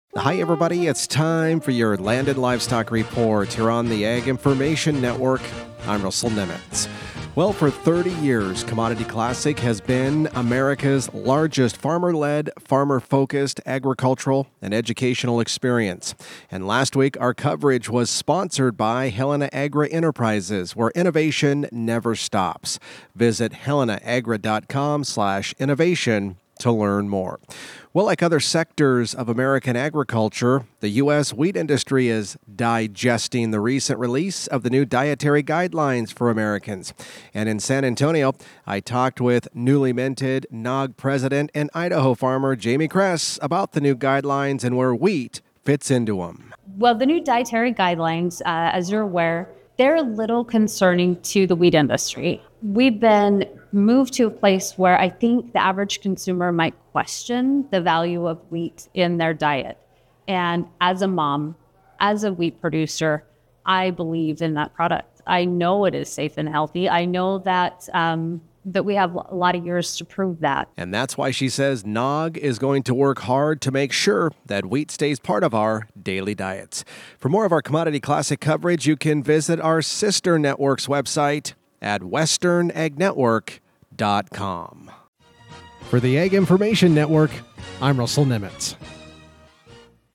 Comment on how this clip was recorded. At Commodity Classic in San Antonio, Texas